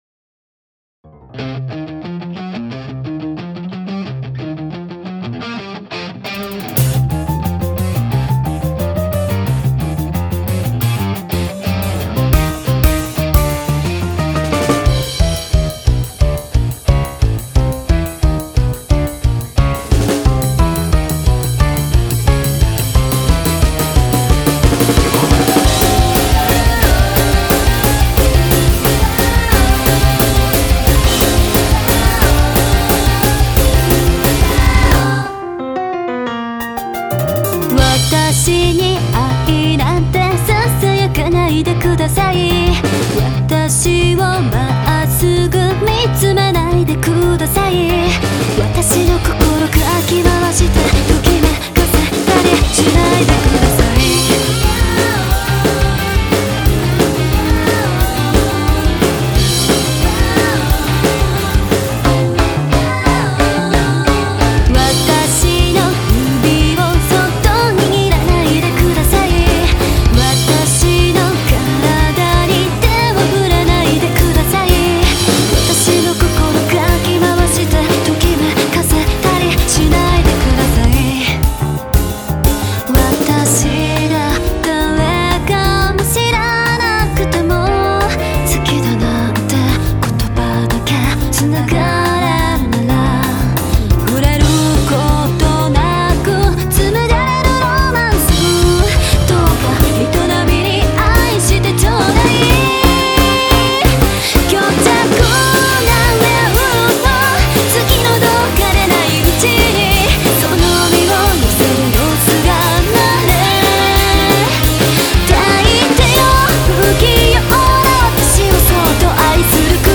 ポップス、ロックを主軸に据えてちょっと不思議な曲や癖の強い曲を多めに！